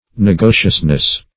Search Result for " negotiousness" : The Collaborative International Dictionary of English v.0.48: Negotiousness \Ne*go"tious*ness\, n. The state of being busily occupied; activity.